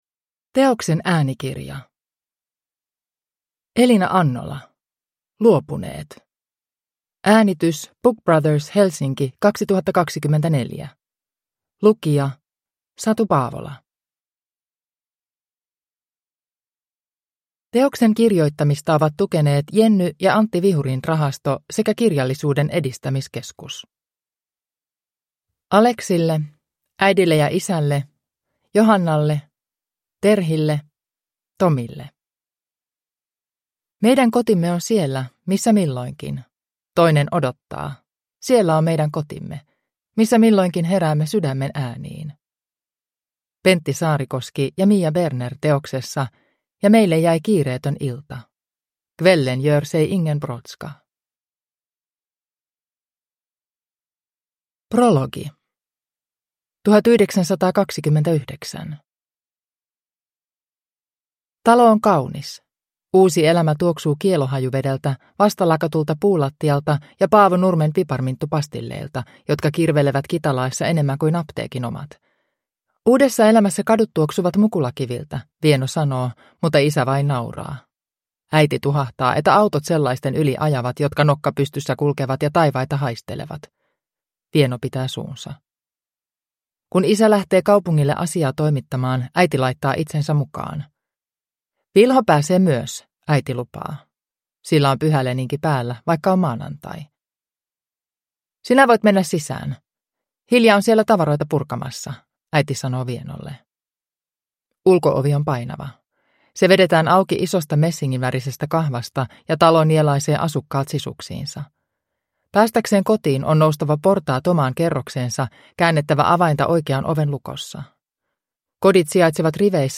Luopuneet (ljudbok) av Elina Annola